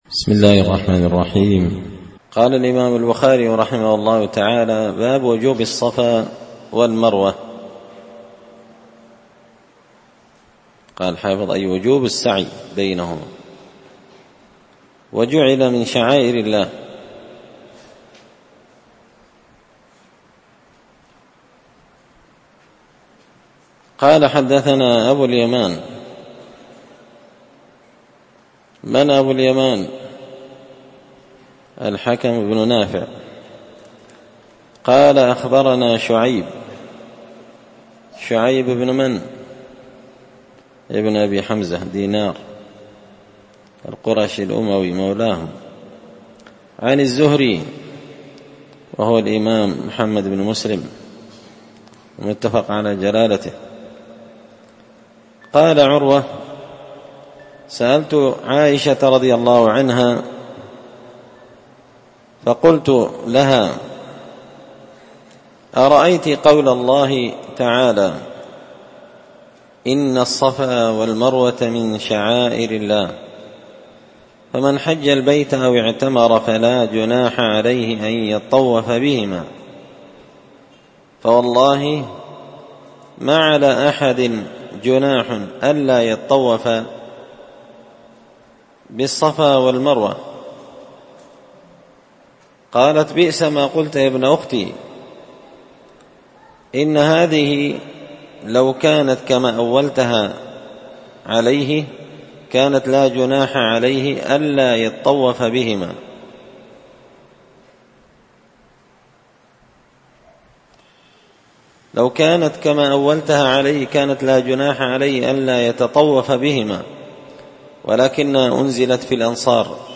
دار الحديث بمسجد الفرقان ـ قشن ـ المهرة ـ اليمن